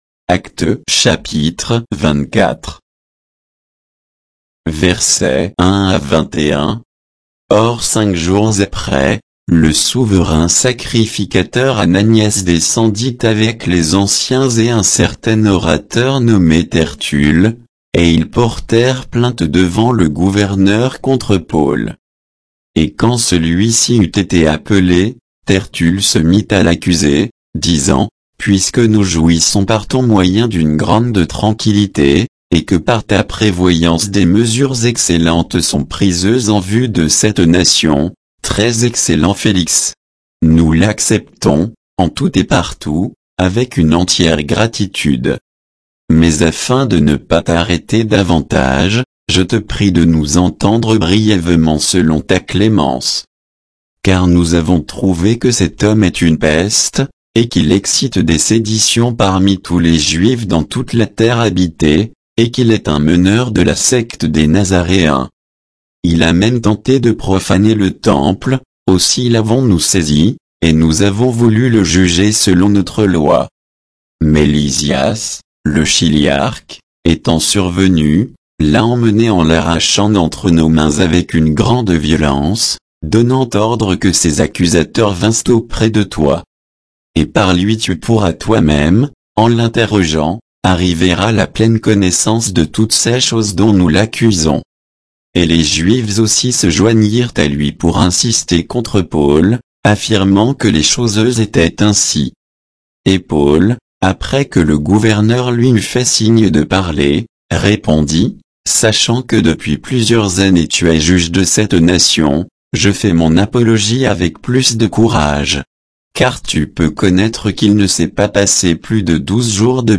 Bible_Actes_24_(sans_notes,_avec_indications_de_versets).mp3